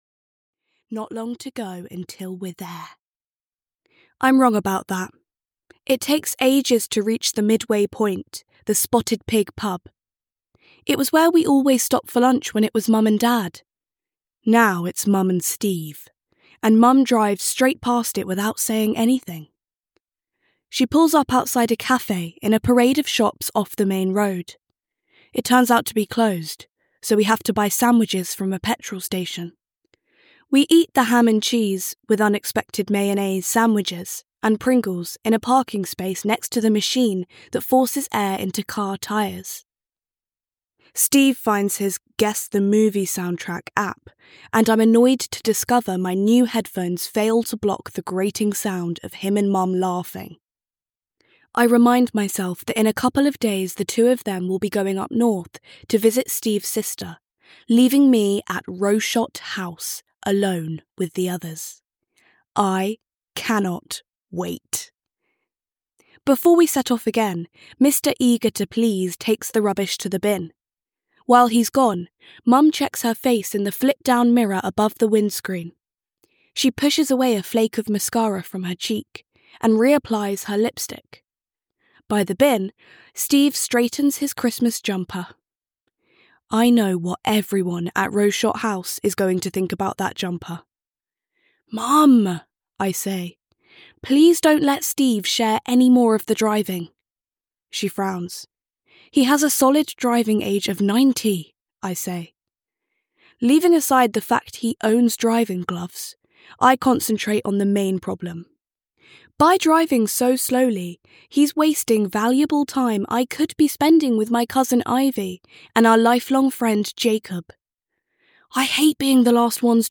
Your Turn to Die (EN) audiokniha
Ukázka z knihy